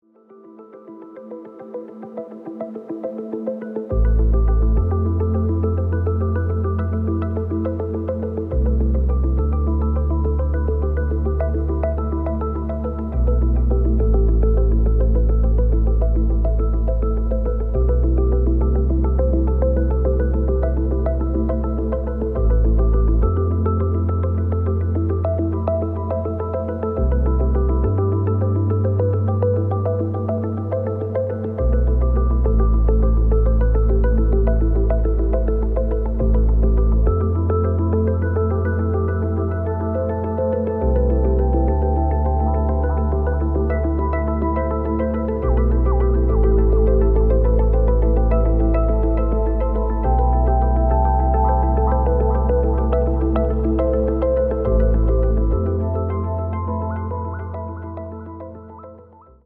• Avant Garde
• Deep house
• Elektronisk
• Instrumentalt
Synthesizer
Ambient Electronica, Deep House & Disco House